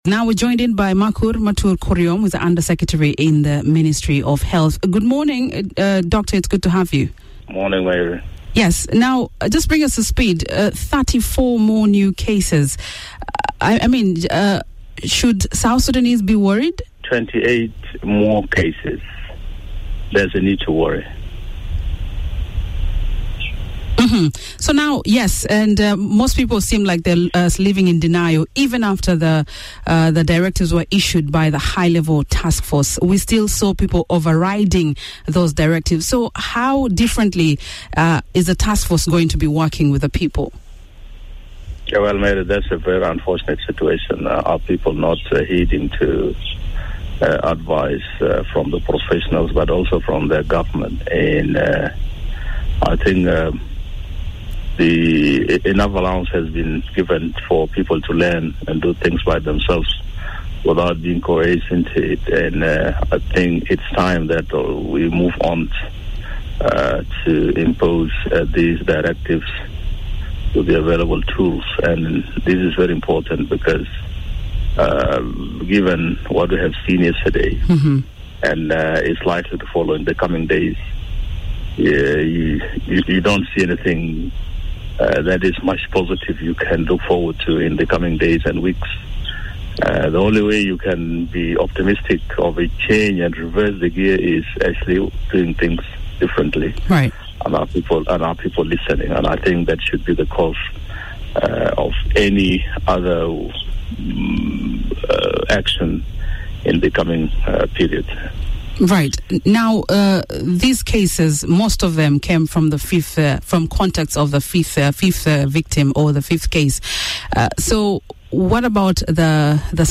He said this in a telephone interview this morning.